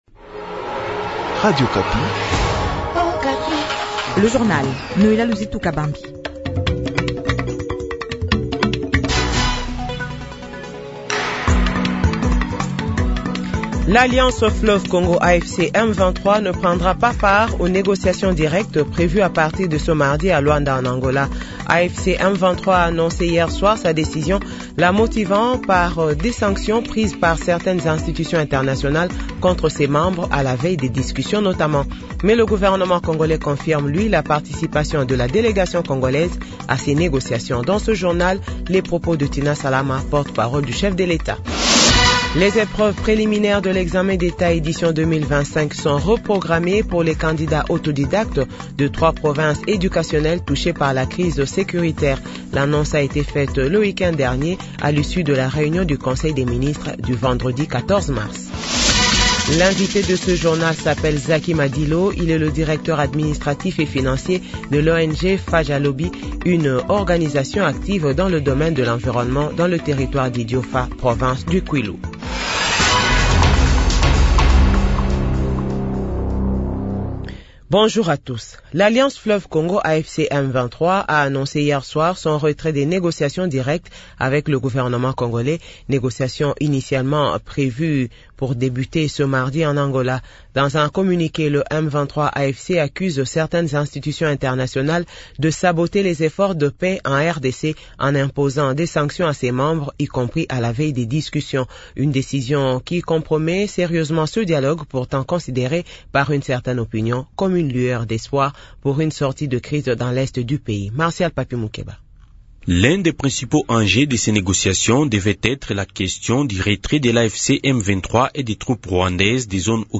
Journal 8h